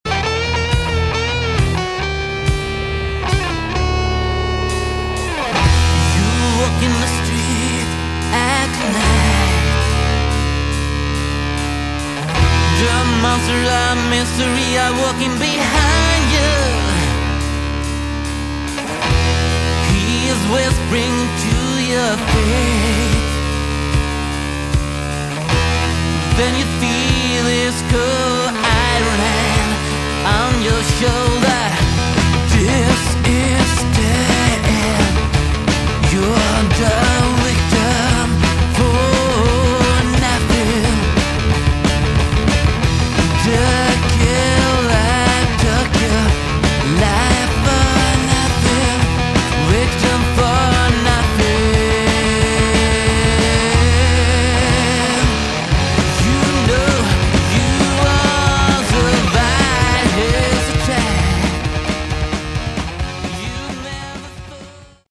bass
guitars
vocals
drums
Recorded in 1993 and pressed as a 7-inch vinyl EP.